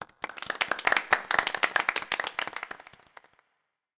Applause Sound Effect
Download a high-quality applause sound effect.
applause-1.mp3